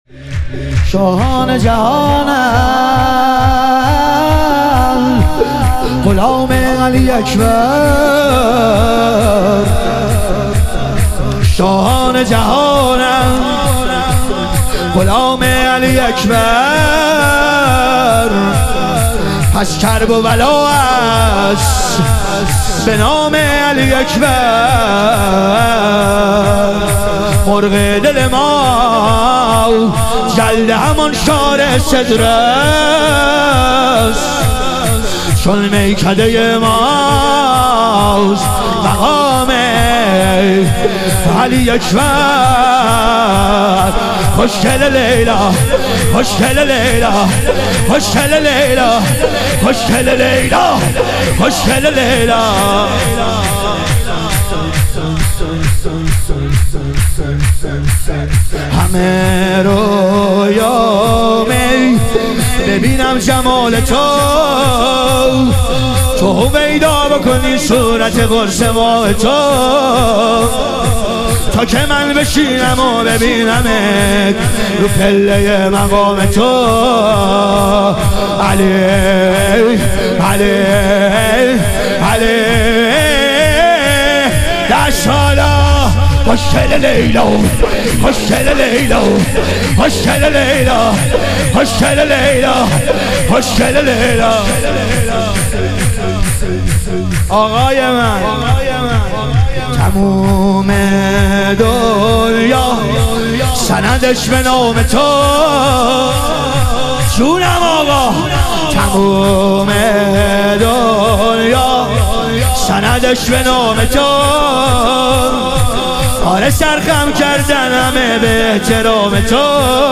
ظهور وجود مقدس حضرت علی اکبر علیه السلام - شور